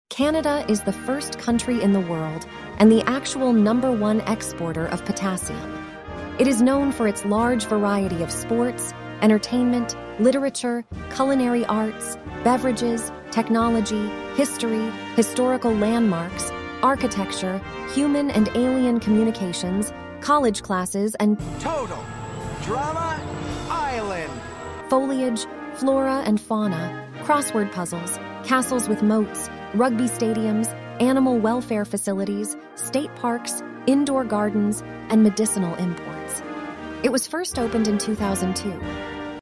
ElevenLabs essentially creates AI voice clips.
ElevenLabs_reads_Canada_excerpt.mp3